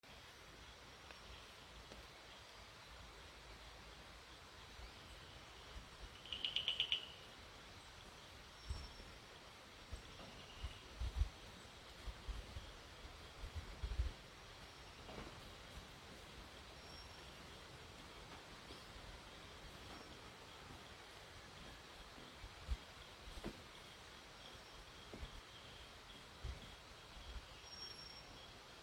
Jayaram'S Bush Frog Scientific Name: Raorchestes Jayarami